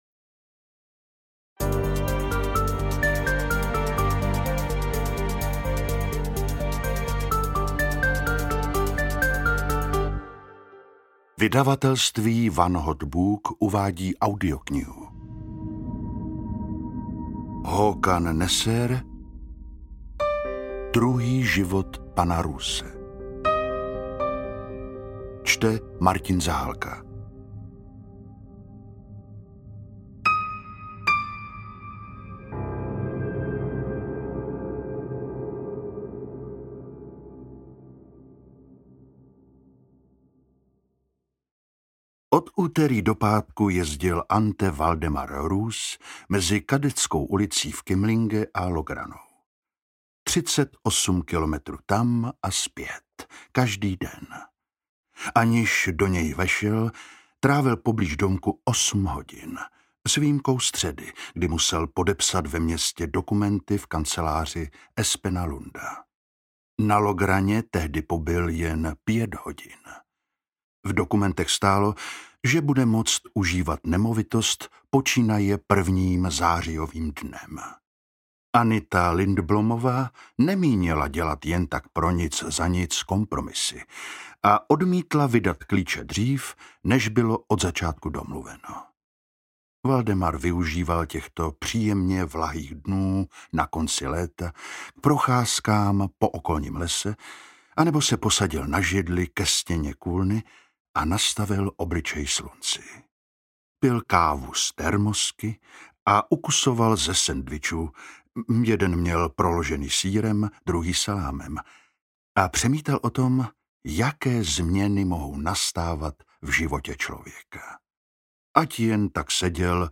Druhý život pana Roose audiokniha
Ukázka z knihy